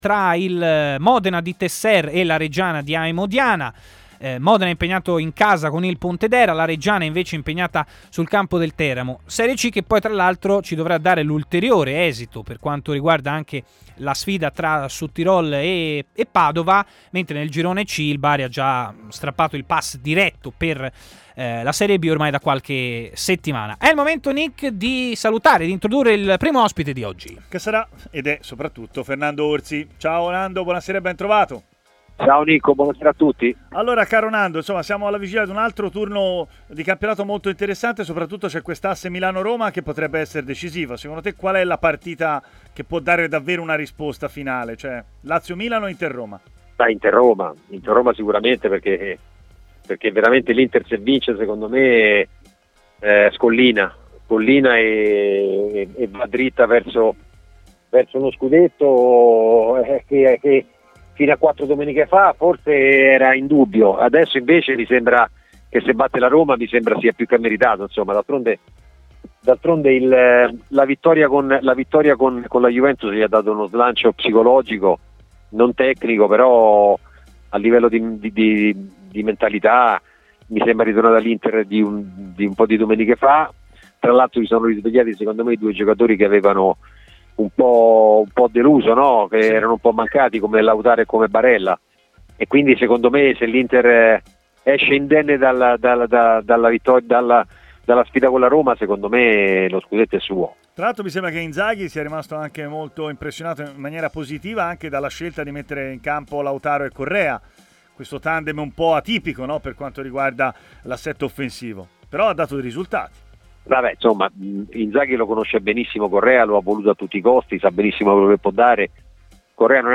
è intervenuto a Stadio Aperto, trasmissione pomeridiana di TMW Radio